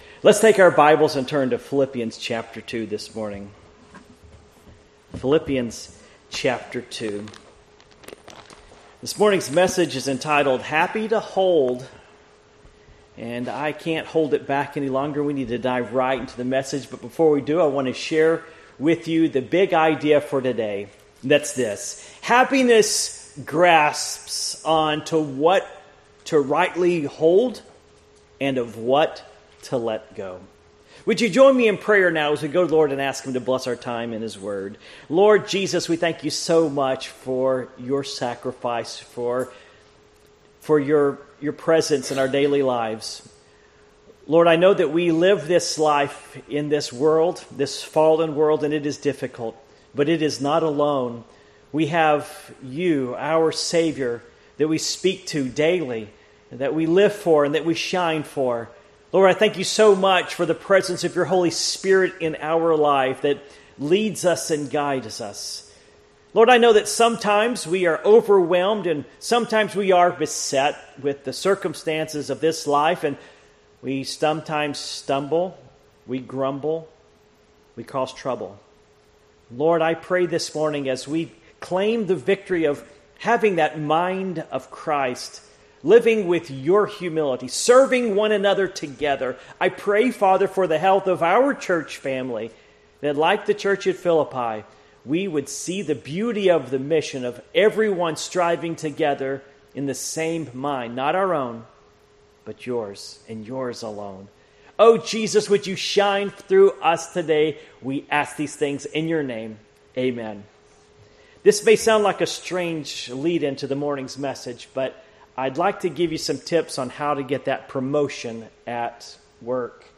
Passage: Philippians 2:12-18 Service Type: Morning Worship